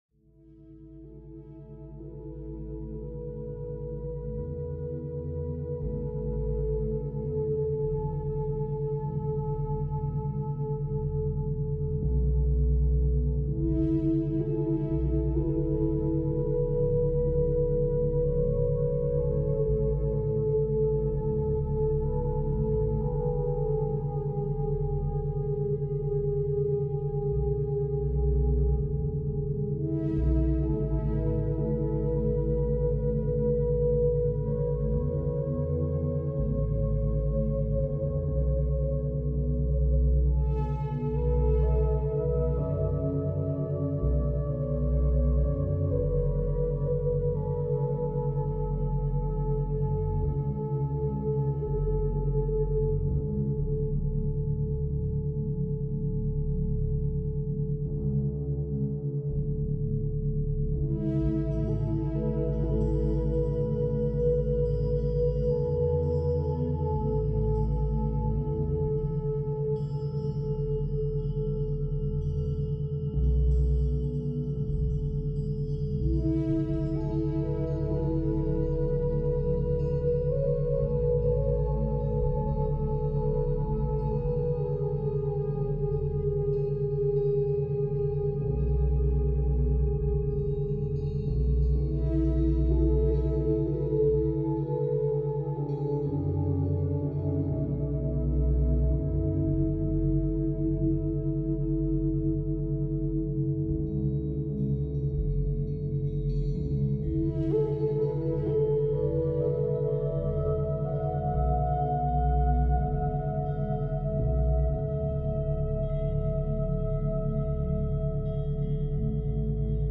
Étude naturelle en 528 Hz · méthode essentielle 2 heures orientée objectifs